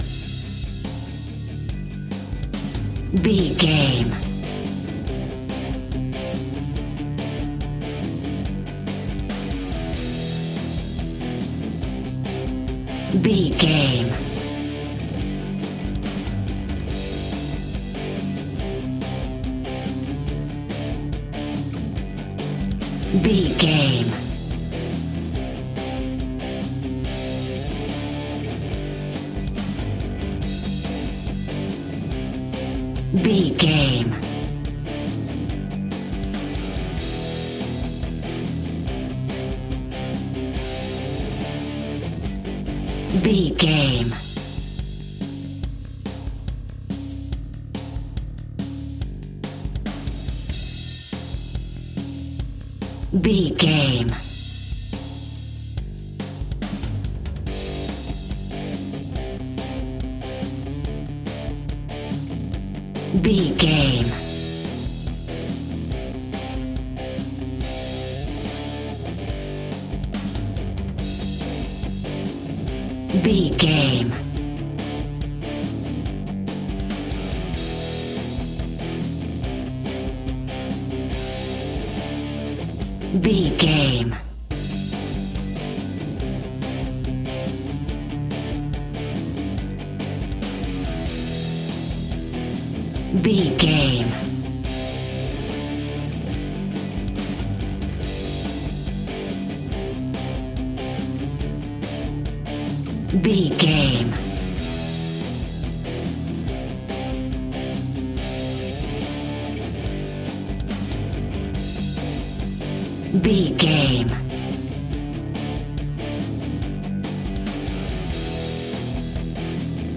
Punk Rock Agressive.
Ionian/Major
indie rock
pop rock
drums
bass guitar
electric guitar
piano
hammond organ